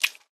squirt.ogg